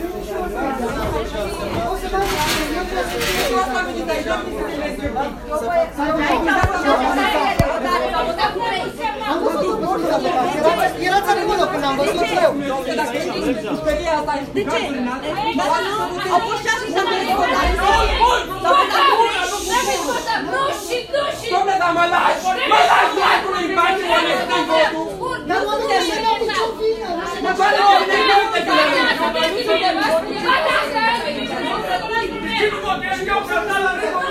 Ați început să bateți în geam și să strigați că vreți să votați – noi v-am înțeles frustrarea.